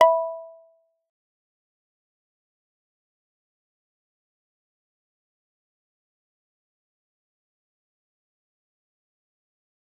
G_Kalimba-E5-f.wav